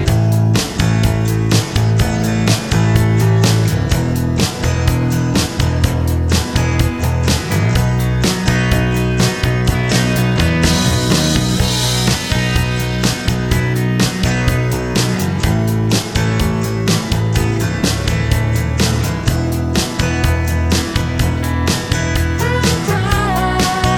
Solo Cut Down Two Semitones Down Rock 5:37 Buy £1.50